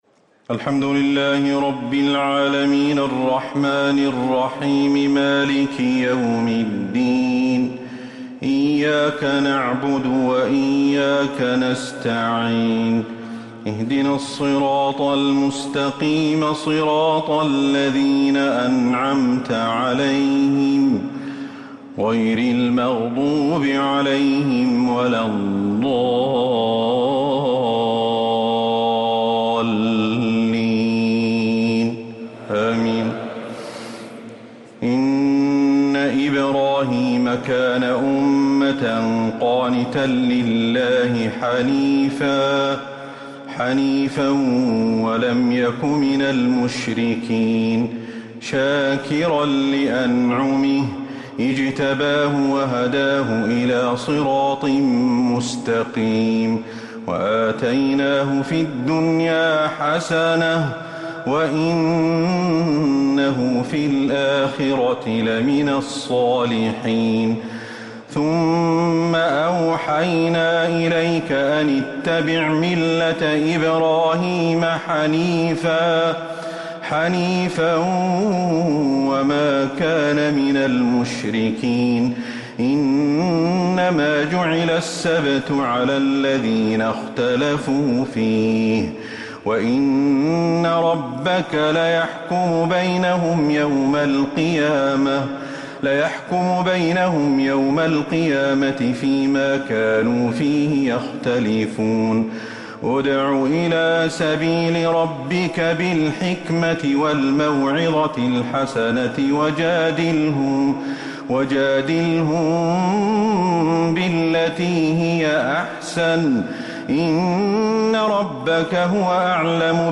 تراويح ليلة 19 رمضان 1444هـ من سورتي النحل (120-128) و الإسراء (1-52) | taraweeh 19 th niqht Ramadan1444H Surah an-Nahl and Al-Israa 1444H > تراويح الحرم النبوي عام 1444 🕌 > التراويح - تلاوات الحرمين